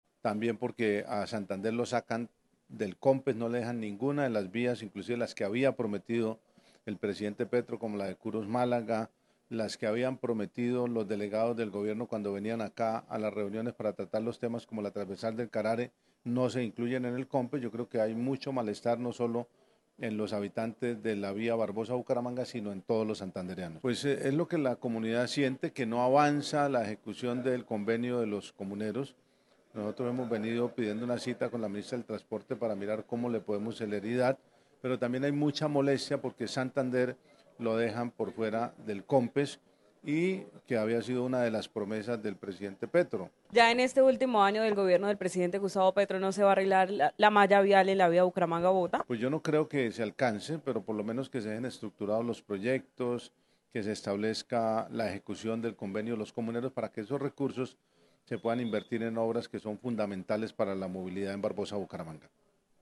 Juvenal Díaz Mateus, gobernador de Santander